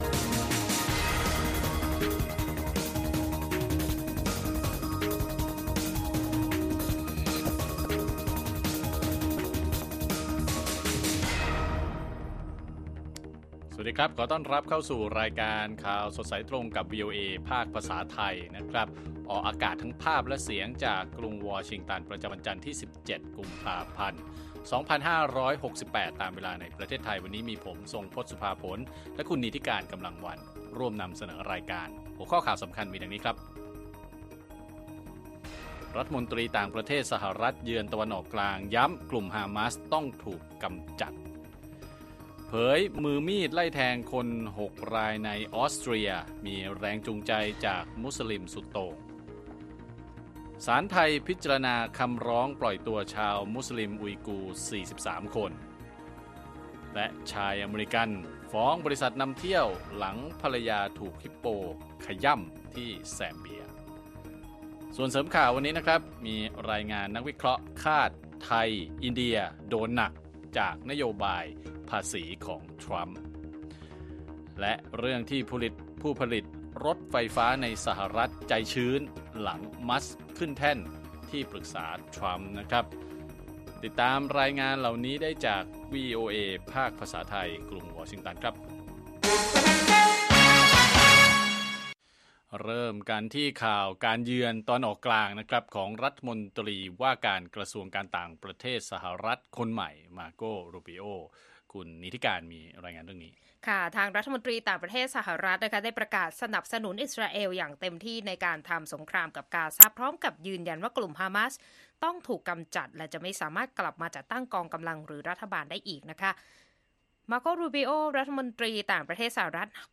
ข่าวสดสายตรงจากวีโอเอไทย จันทร์ ที่ 17 ก.พ. 68